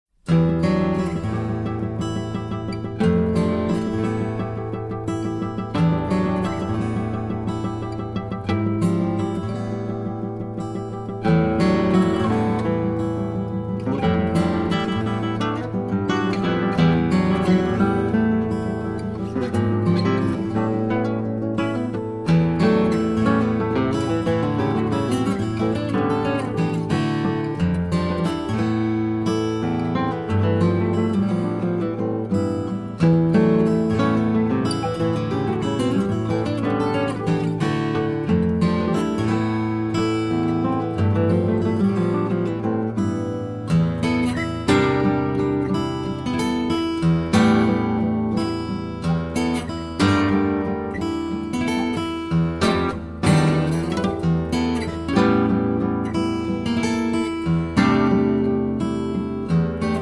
Guitar Duo